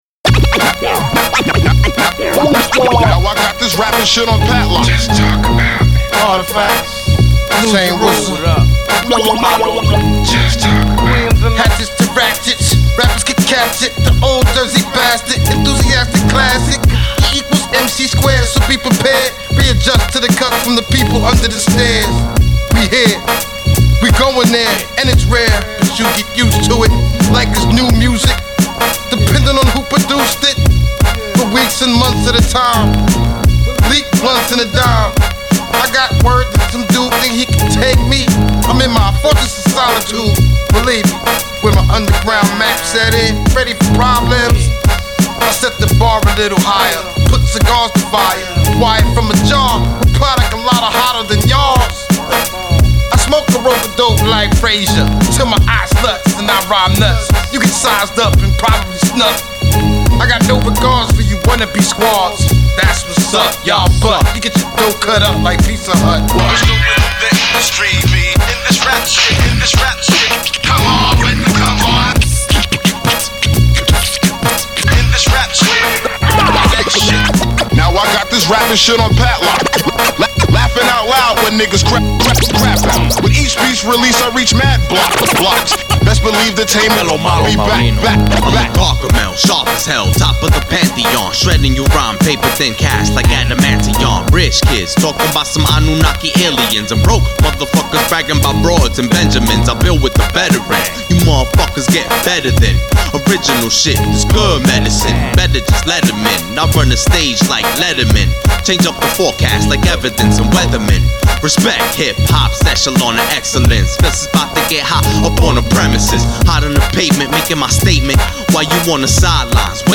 by in HIP HOP | 4 Comments